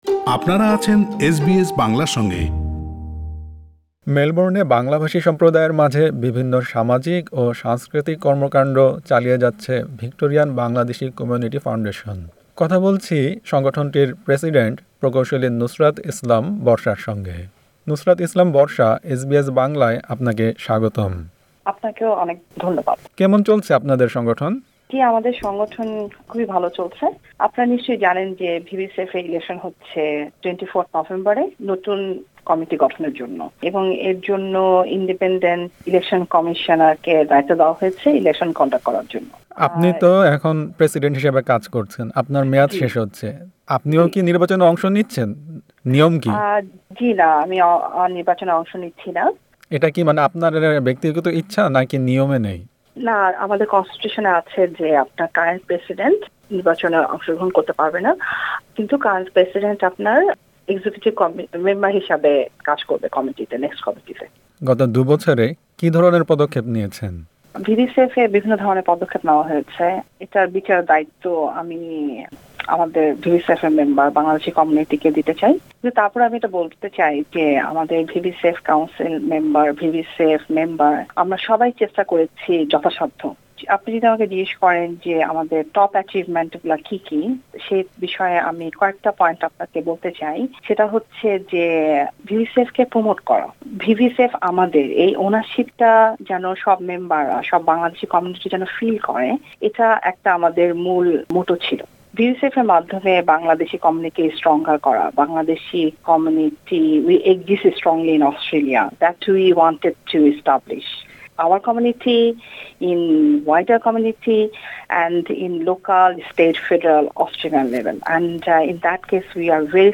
কথা বলেছেন এসবিএস বাংলার সঙ্গে।